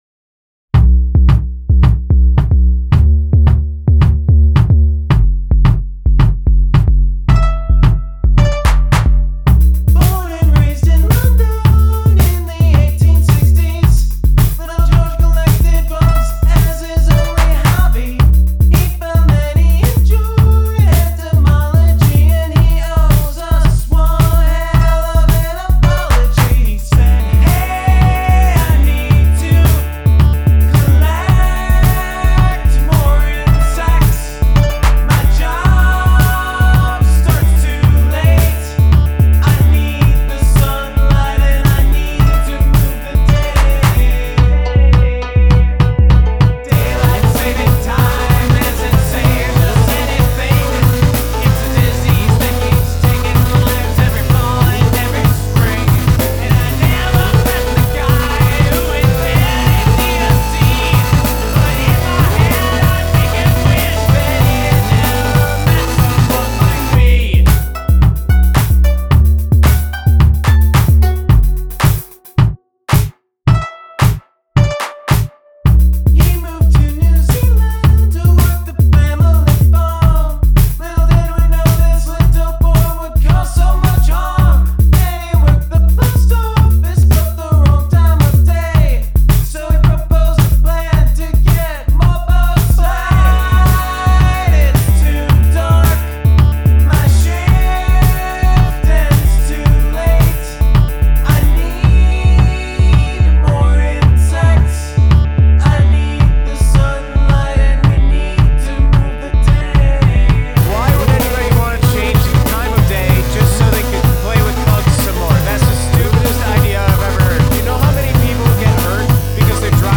I love that tempo change and the angry chorus especially.
Great falsetto!
Cool synth sounds, and you sound real angry.